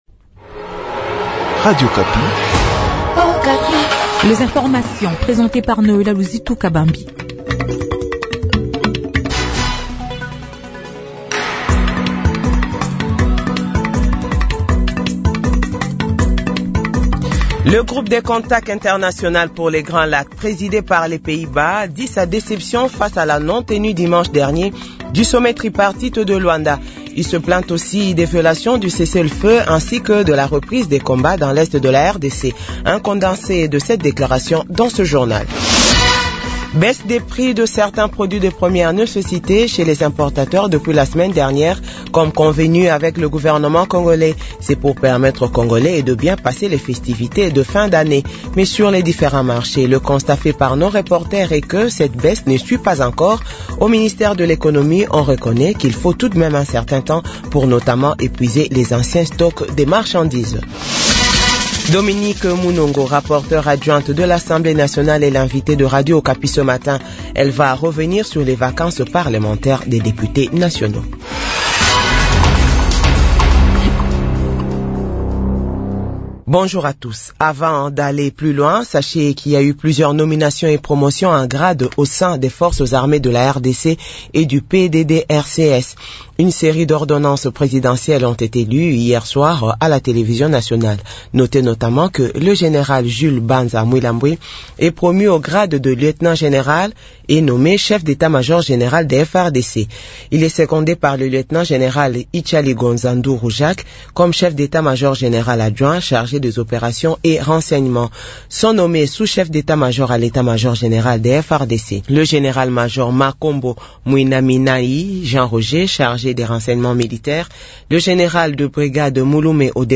JOURNAL FRANÇAIS DE 6H- 7H